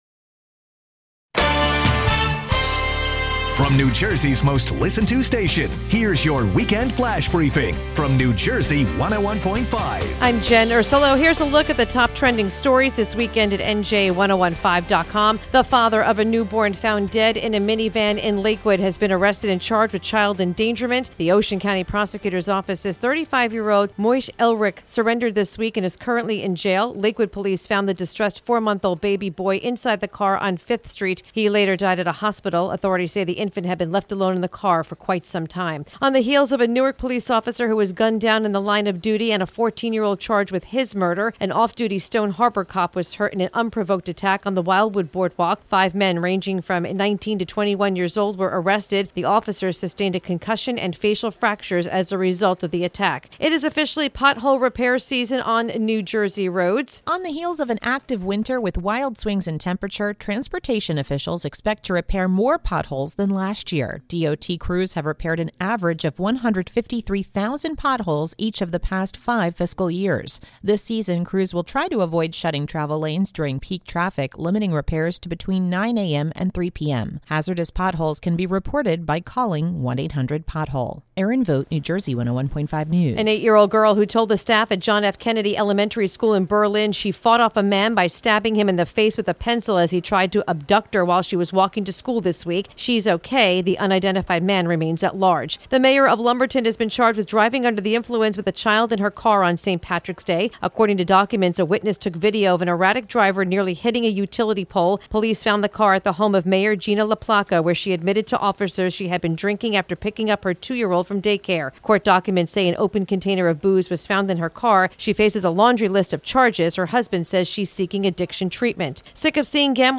The latest New Jersey news and weather from New Jersey 101.5 FM, updated every hour, Monday through Friday. Hear our special weekend report every weekend.